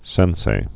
(sĕnsā, sĕn-sā)